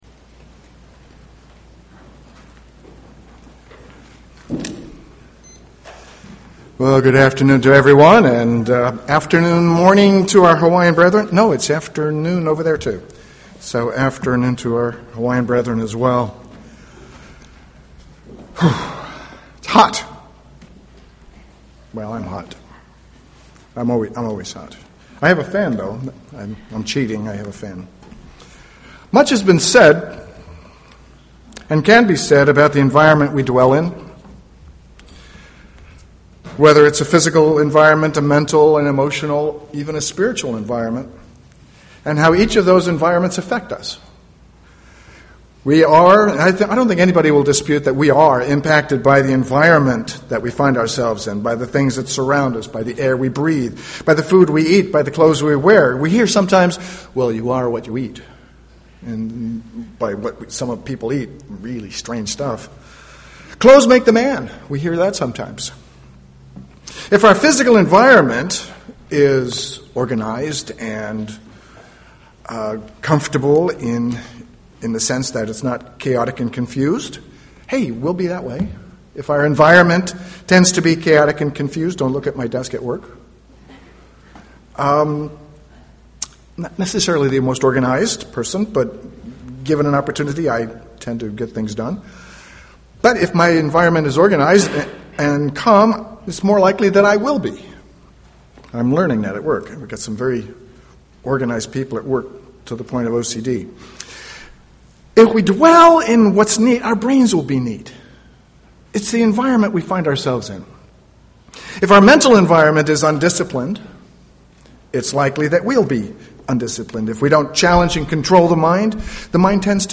Given in San Jose, CA
UCG Sermon Studying the bible?